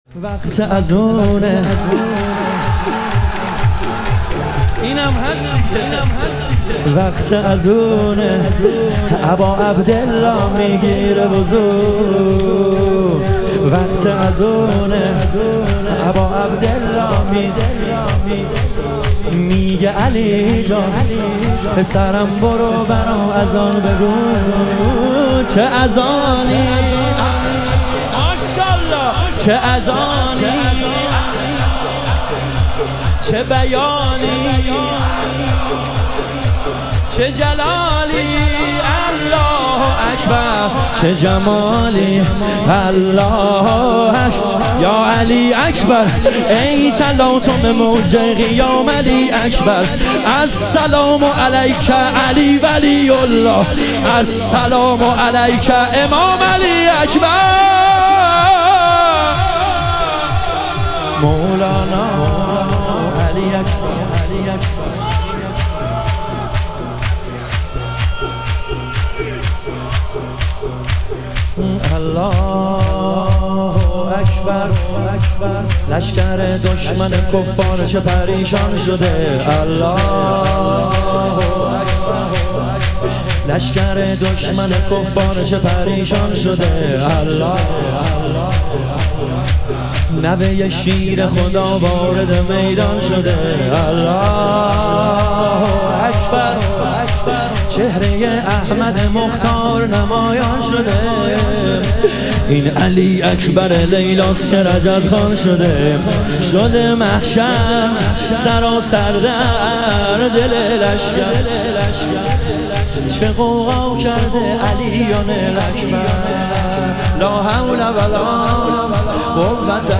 ایام فاطمیه اول - شور